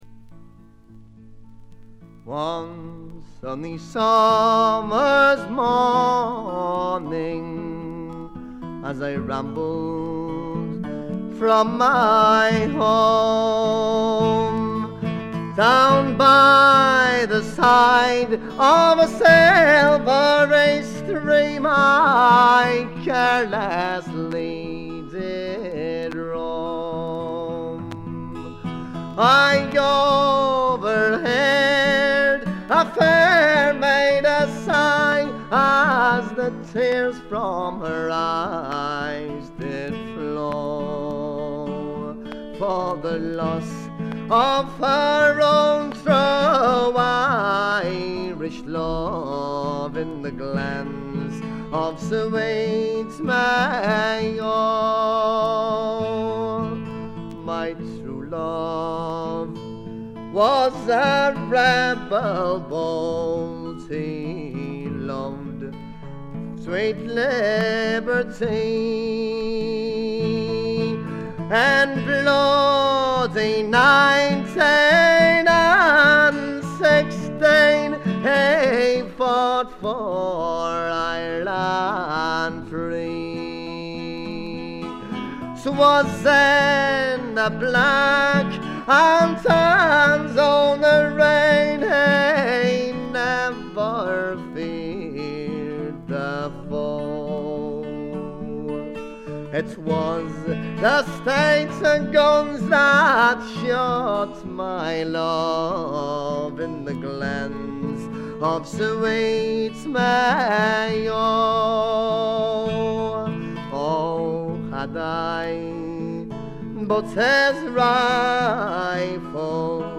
軽微なバックグラウンドノイズにところどころでチリプチ（特にA1〜A3）。
試聴曲は現品からの取り込み音源です。
Vocals ?
Guitar ?
Tin Whistle ?